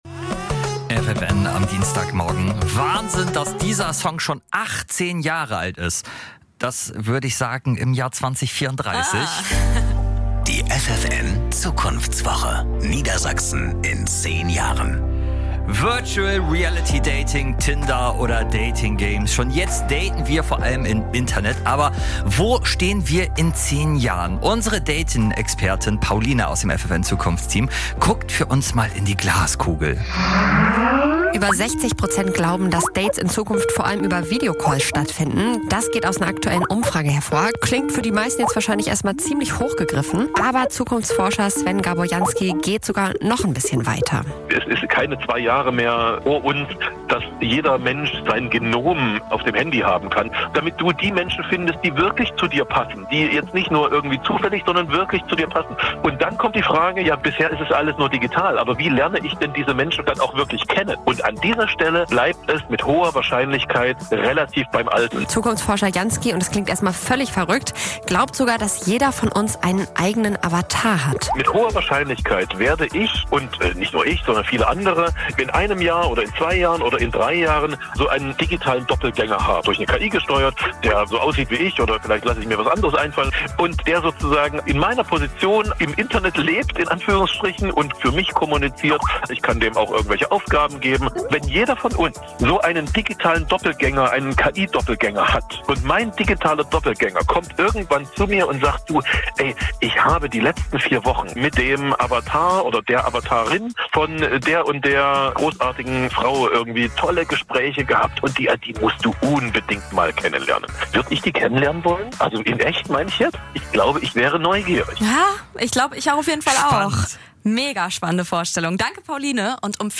Dieser Beitrag beeindruckt mit seiner hohen Qualität, seiner Relevanz und seiner Fülle an starken O-Tönen.
Sie lässt verschiedene Experten zu Wort kommen. Sie nimmt uns mit auf eine Zeitreise und zeigt, wie wir in Zukunft leben könnten: Vom Dating, über den medizinischen Fortschritt bis hin zur Zukunft der Arbeit – dieser Beitrag wagt ein Gedankenexperiment.